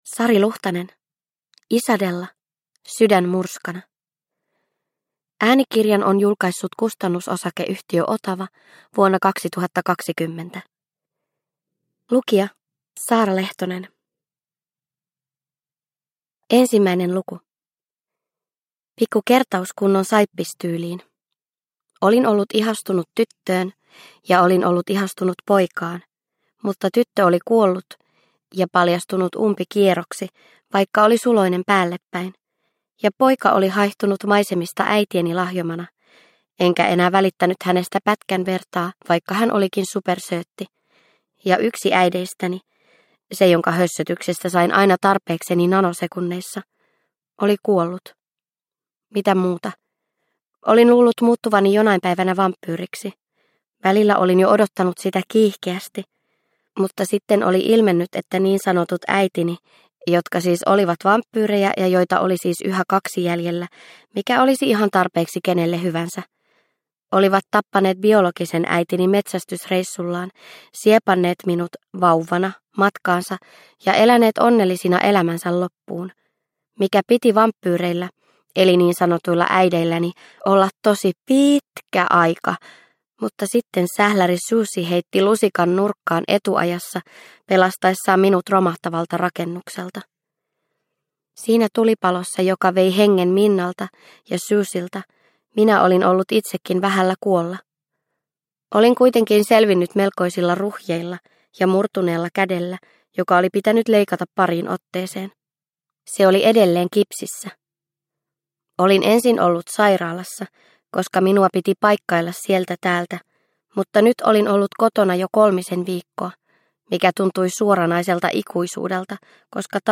Isadella - Sydän murskana – Ljudbok – Laddas ner